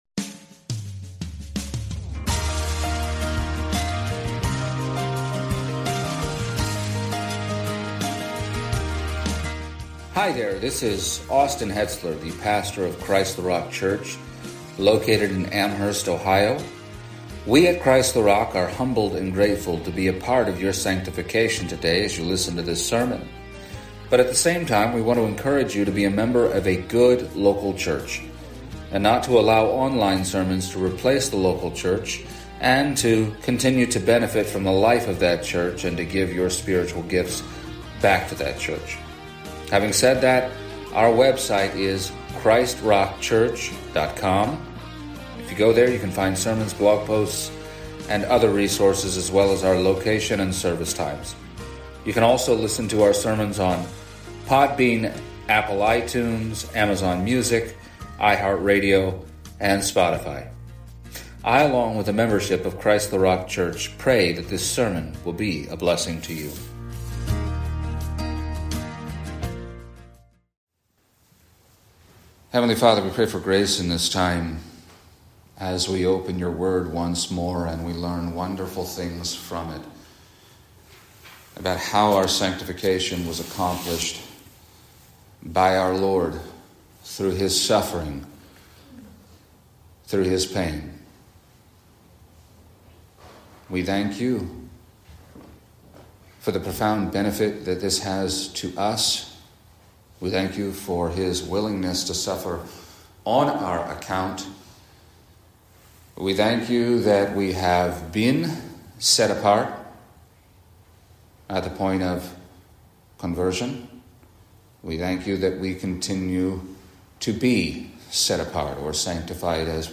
Passage: Hebrews 2:10-13 Service Type: Sunday Morning